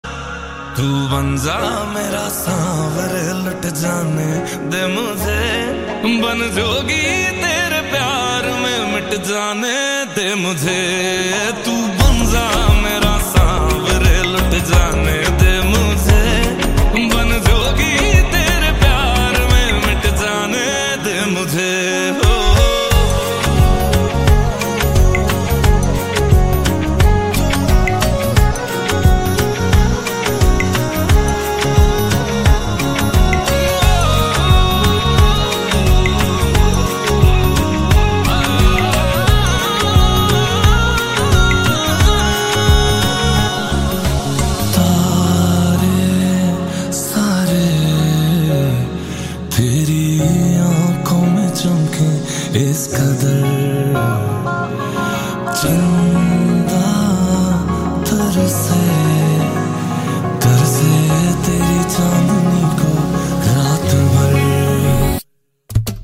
Categories Hindi ringtones